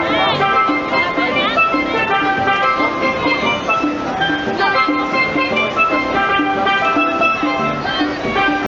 Steelpan
It has a bright timbre, reminiscent of relaxation in a Caribbean island.